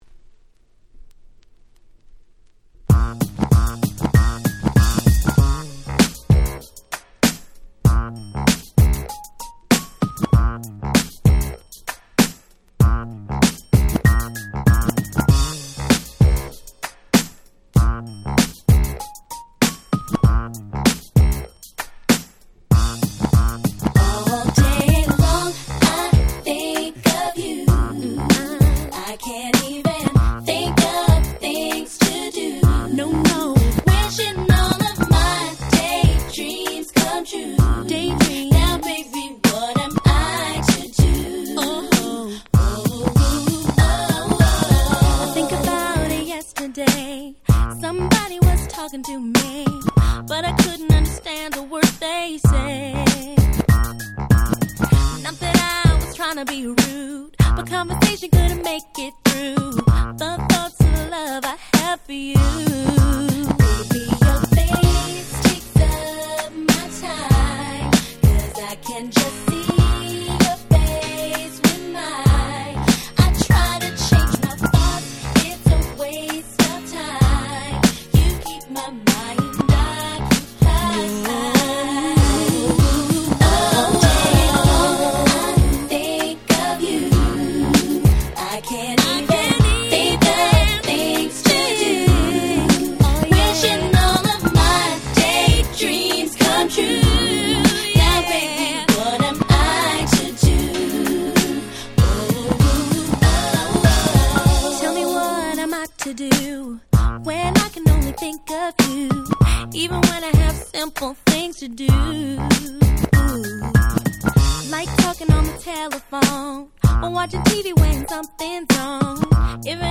98' Super Hit R&B !!
若さ弾ける可愛さ100%なHip Hop Soulです！